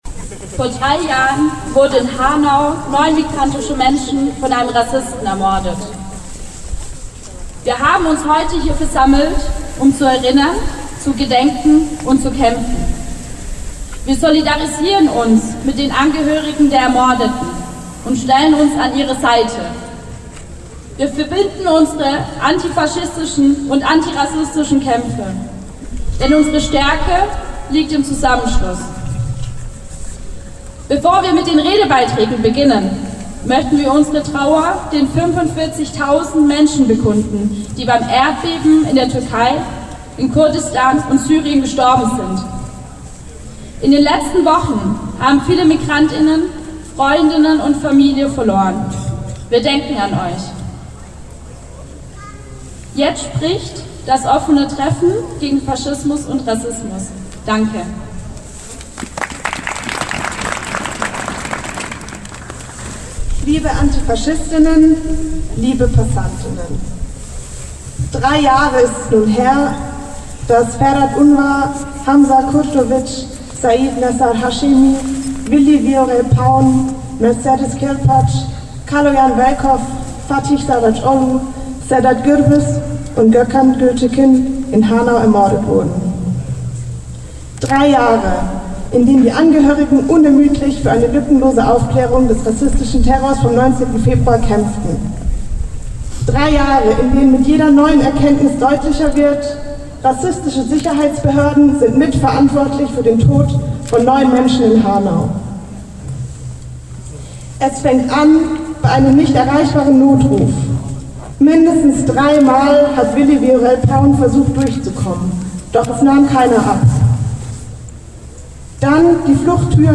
"Hanau ist überall": Demo in Tübingen
"3 Jahre Hanau - niemals vergessen!" Unter diesem Motto fand zum dritten Jahrestag der rassistischen Morde in Hanau eine Demo in Tübingen statt, zu der geschätzt mehrere hunderte Menschen kamen.
Erste Rede
90257_Hanau-Demo-Anfang.mp3